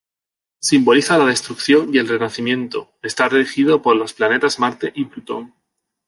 Pronunciado como (IPA)
/renaθiˈmjento/